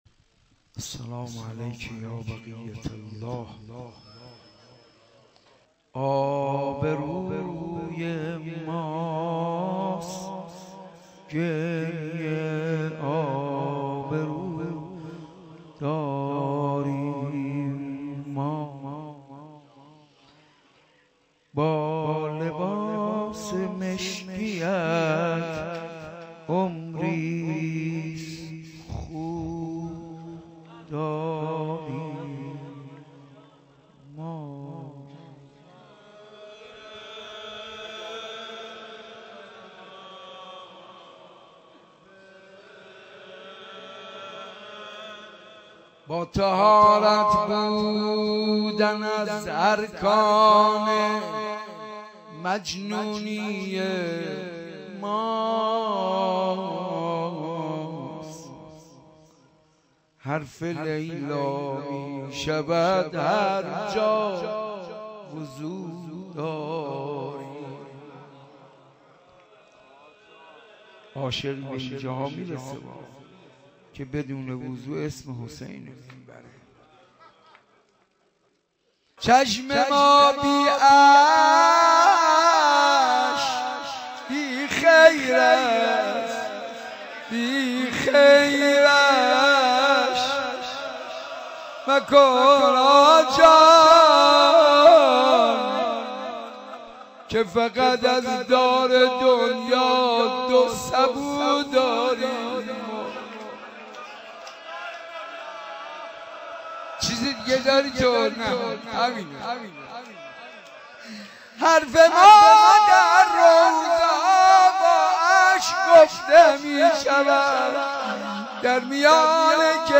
شب بیست و هشتم محرم در حسینیه بیت الزهرا
با مداحی حاج منصور ارضی برگزار ش